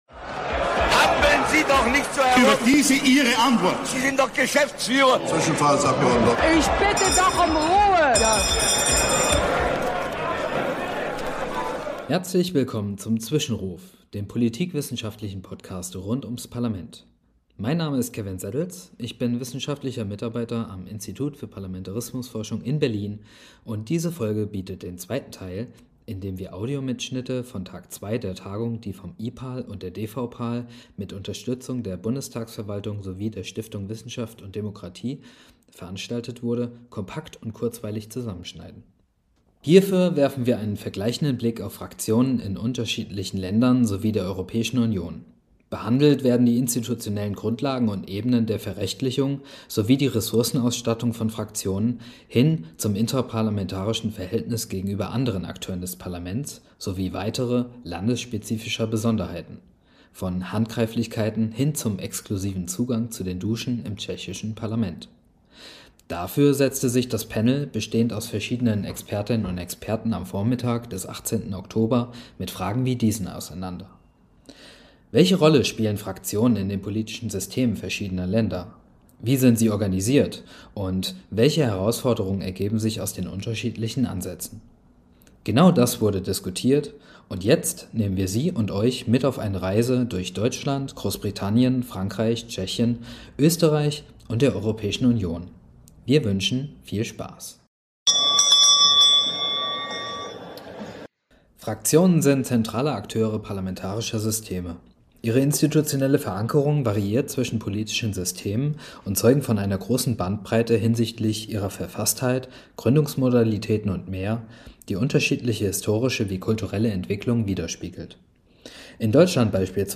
Wenn wir mit unseren Gästen aus Politik, Wissenschaft und Journalismus über Themen rund ums Parlament sprechen, verbinden wir politikwissenschaftliche mit praktischen, politischen Perspektiven.